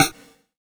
SNARE 40  -L.wav